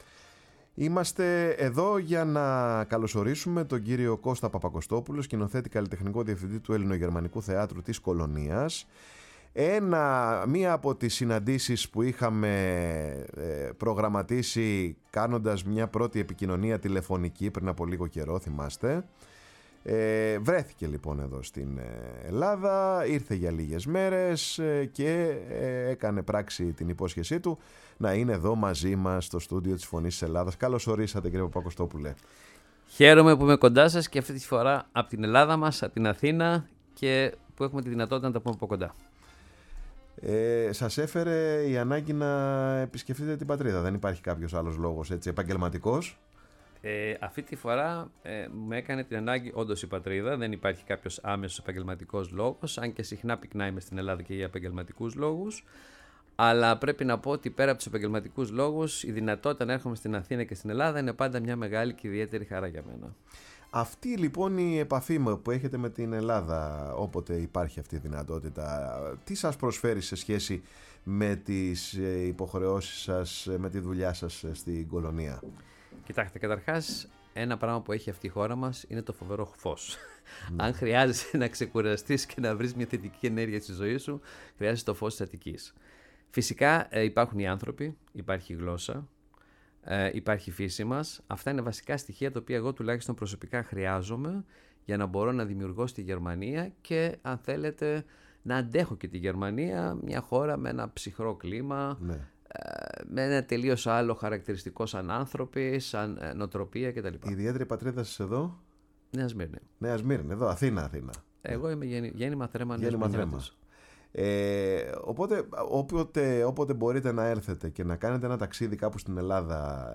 φιλοξένησε σήμερα στο στούντιο η εκπομπή ”Πάρε τον Χρόνο σου”